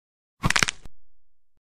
دانلود آهنگ شکستن استخوان یا کمر 2 از افکت صوتی انسان و موجودات زنده
دانلود صدای شکستن استخوان یا کمر 2 از ساعد نیوز با لینک مستقیم و کیفیت بالا
جلوه های صوتی